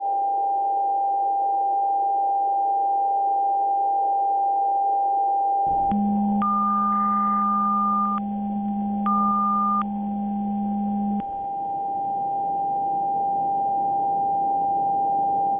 Spent a few hours scrubbing through the radio spectrum, finding it weirdly hypnotic.